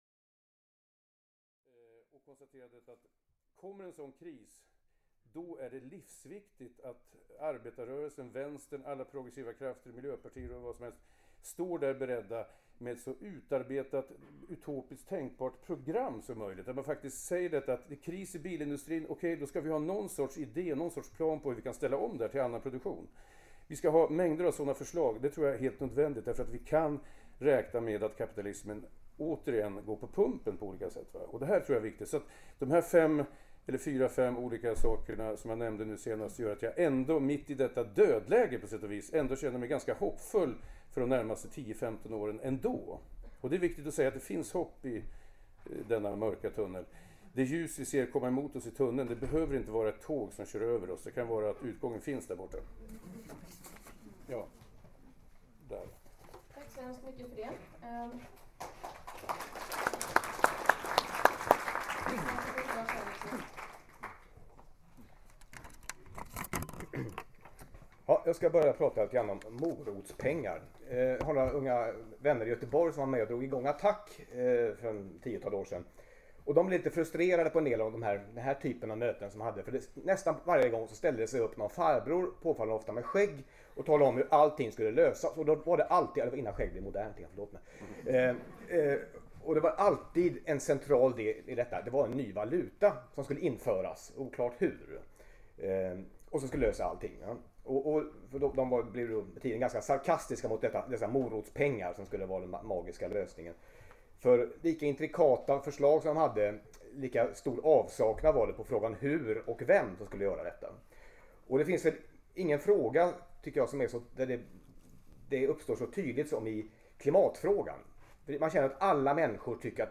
Fullsatt paneldebatt – här som ljudfil